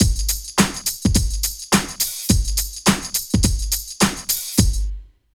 18 DRUM LP-R.wav